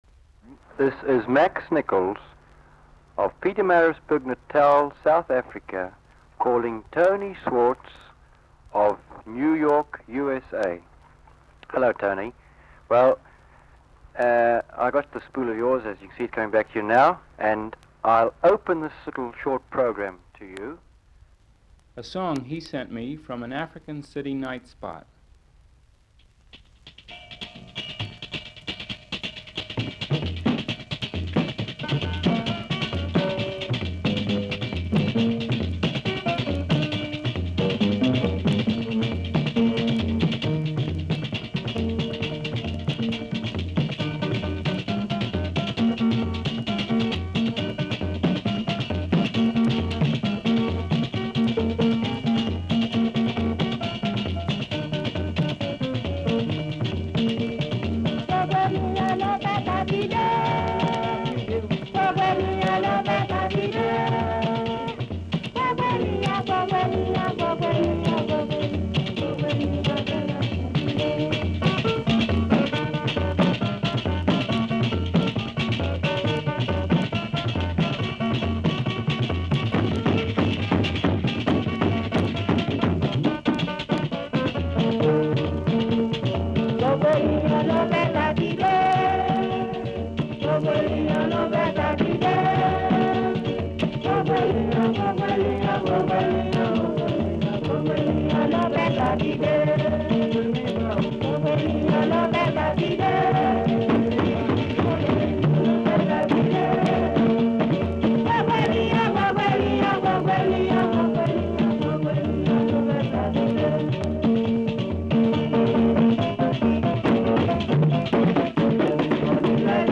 04. Comment from South Africa, a song from an African city nightspot and a song from and outlying district